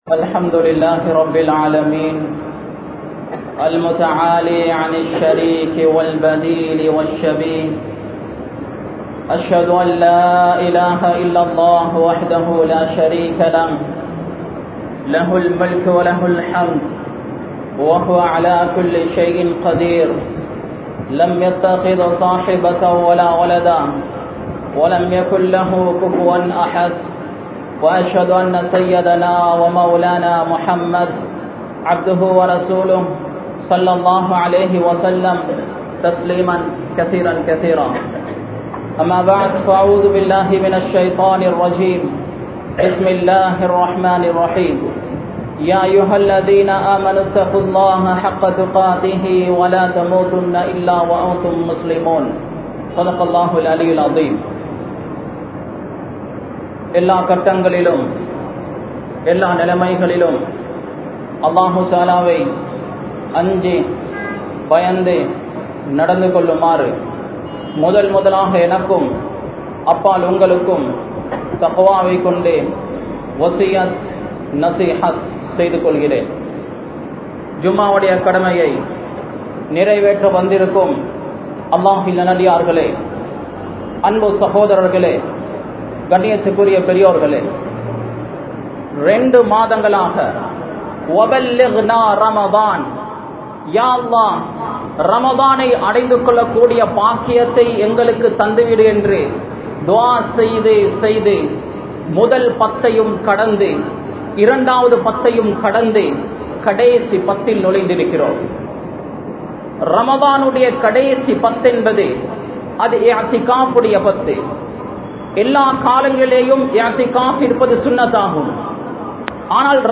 Lailaththul Qathrudaiya Iravu Ethu? (லைலத்துல் கத்ருடைய இரவு எது?) | Audio Bayans | All Ceylon Muslim Youth Community | Addalaichenai
Colombo, Slave Island, Akbar Jumua Masjith